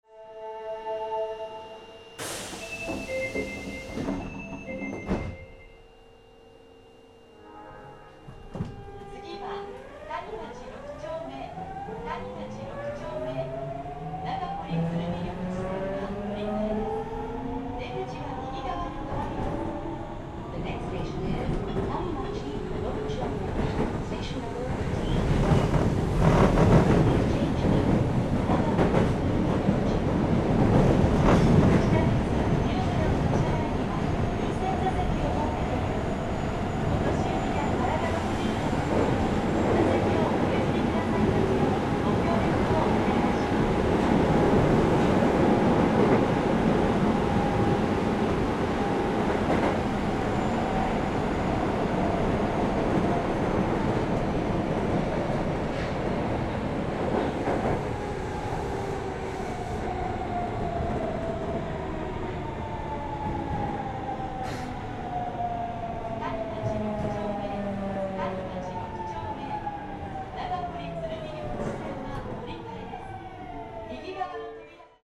録音車両：２２１１０
今回のCDは、その新20系を大阪市交通局最長路線となる谷町線で全区間録音しました。初期の日立GTO-VVVFらしい唸りのあるVVVF音をお楽しみください。
Osaka22.mp3